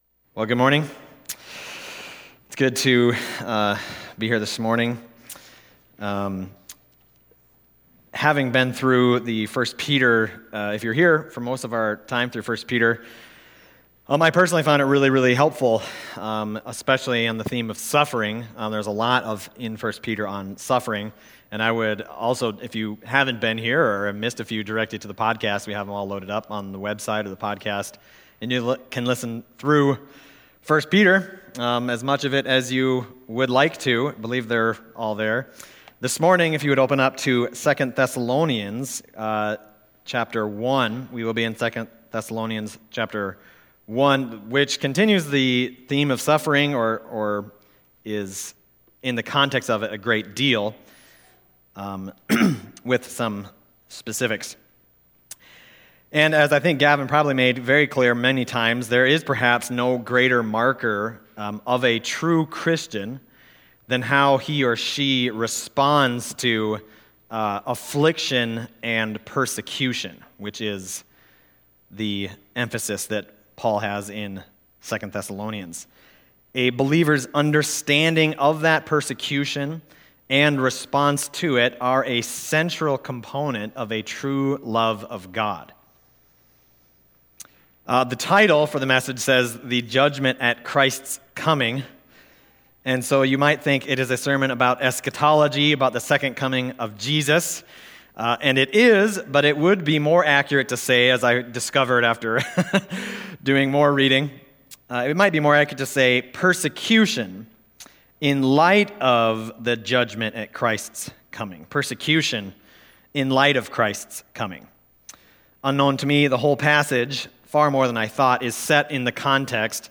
Other Passage: 2 Thessalonians 1:5-12 Service Type: Sunday Morning